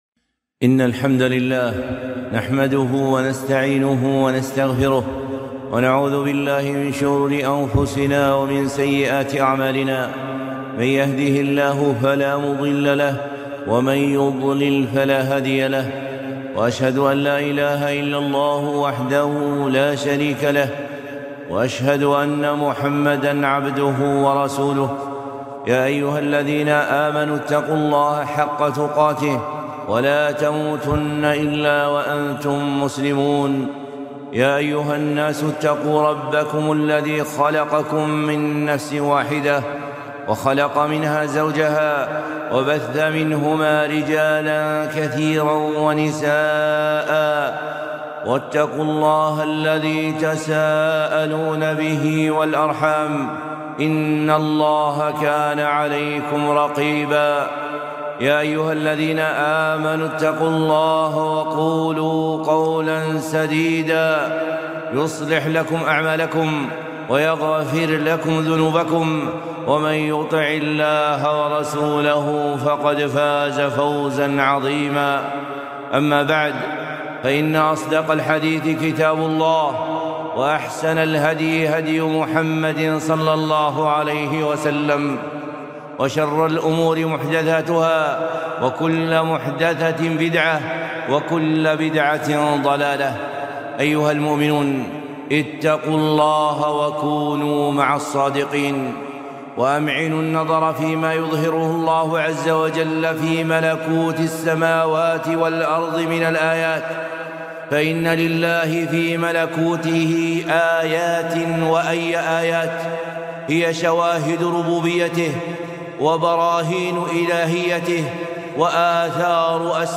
خطبة - آية البرد